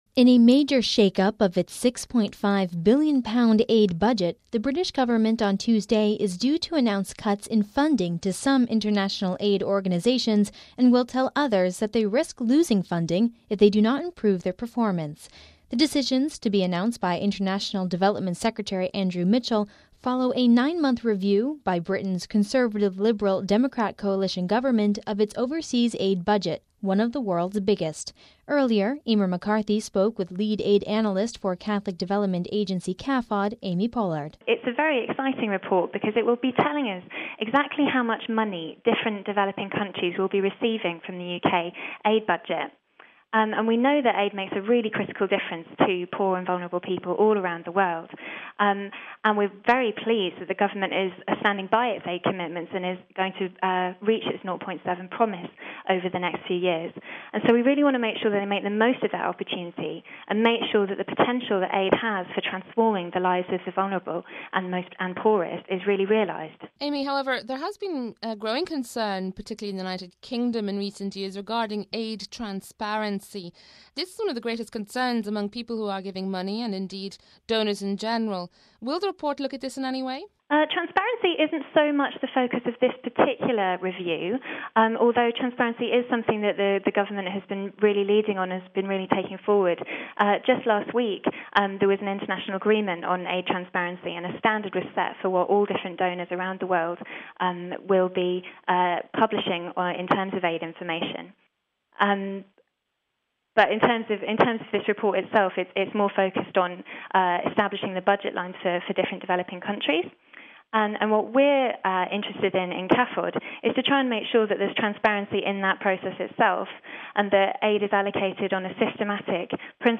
spoke with Lead Aid Analyst